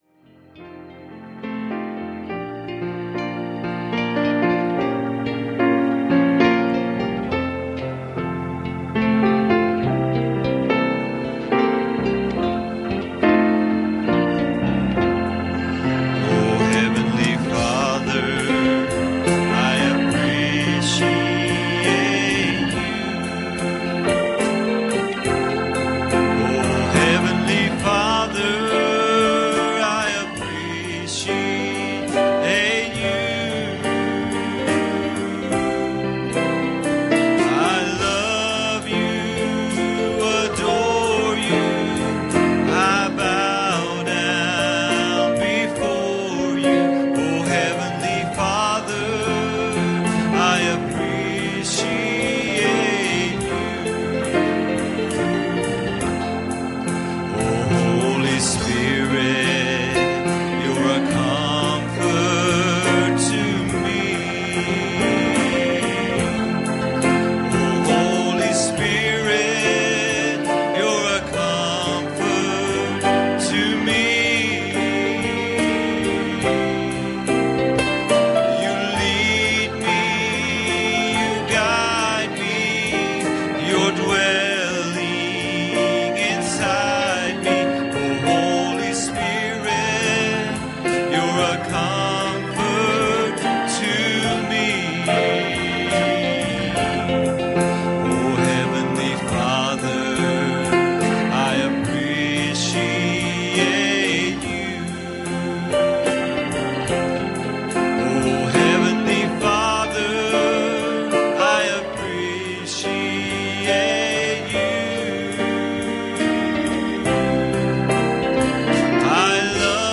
Passage: 2 Corinthians 3:2 Service Type: Wednesday Evening